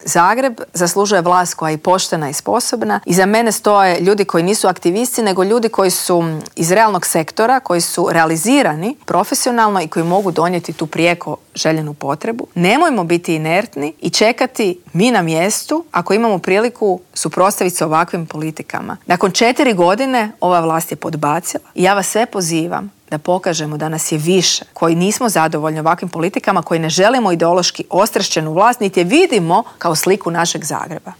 U specijalnom izbornom Intervjuu tjedna Media servisa ugostili smo nezavisnu kandidatkinju za gradonačelnicu Grada Zagreba Mariju Selak Raspudić s kojom smo razgovarali o problemima Zagrepčana.